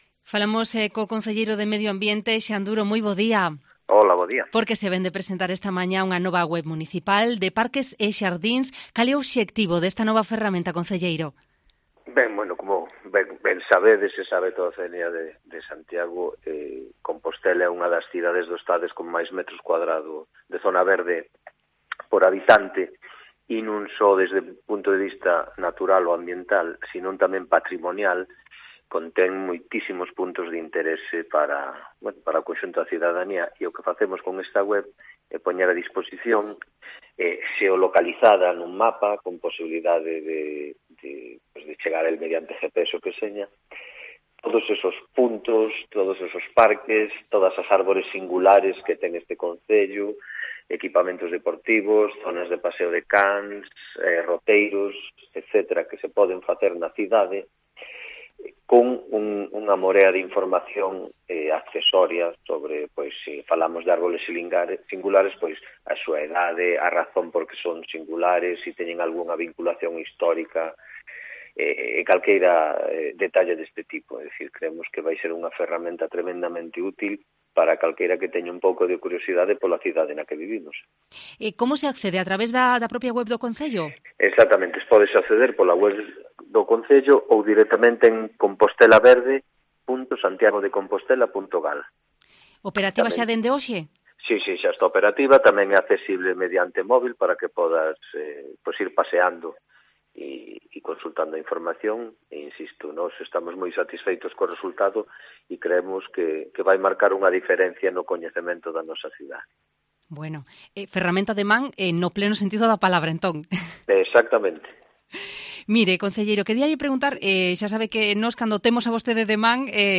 Entrevista al concelleiro de Medio Ambiente, Xan Duro
Sobre esta herramienta que acaba de ponerse en funcionamiento hablamos en Cope+Santiago con el concelleiro de Medio Ambiente: le trasladamos también alguna queja que nos llegó a la radio precisamente sobre falta de iluminación en uno de los parques de la ciudad, en la zona de Meixonfrío.